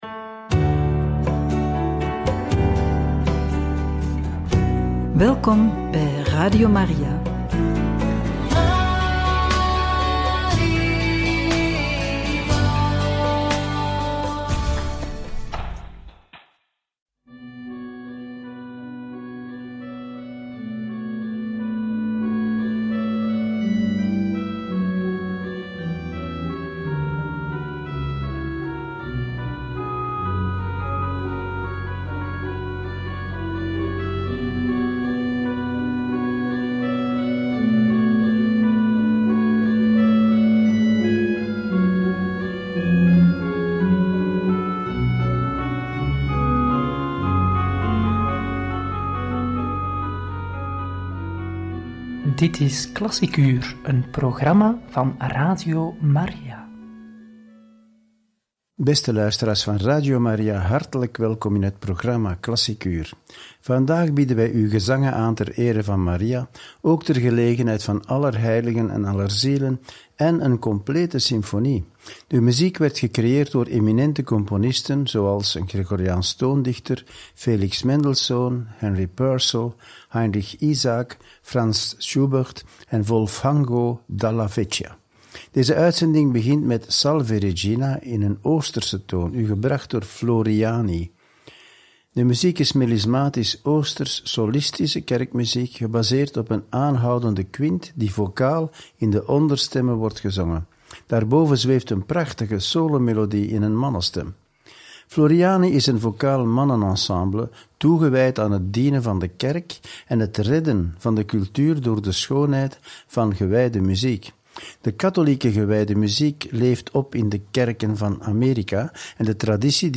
Gezangen ter ere van Maria en ter gelegenheid van Allerheiligen en Allerzielen – Radio Maria